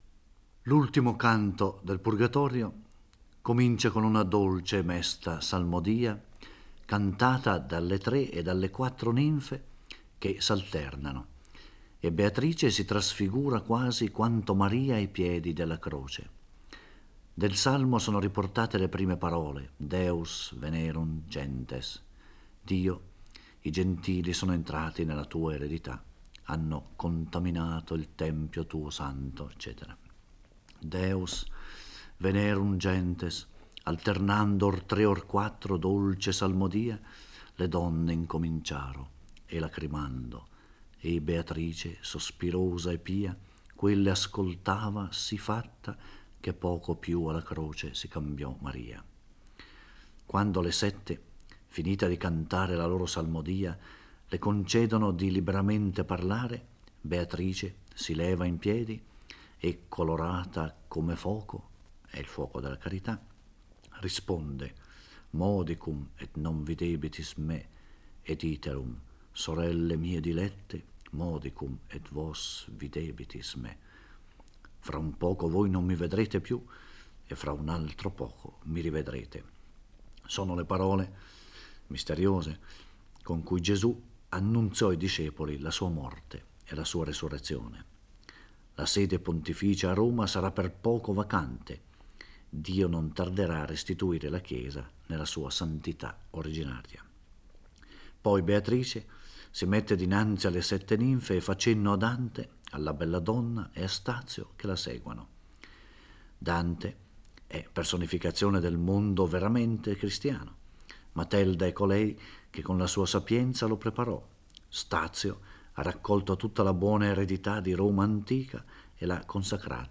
Giorgio Orelli legge e commenta il XXXIII canto del Purgatorio. Conclusivo del Purgatorio, il canto in effetti si presenta in continuità sia rispetto al canto precedente, sia rispetto al primo del Paradiso.